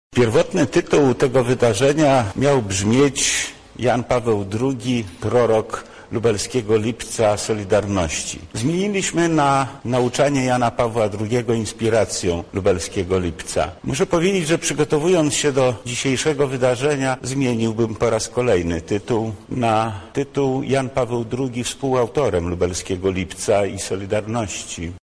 W urzędzie wojewódzkim odbyło się wydarzenie pt. „Św. Jan Paweł II inspiracją Lubelskiego Lipca’80″.
Swoimi przemyśleniami podzielił się też wojewoda lubelski Lech Sprawka: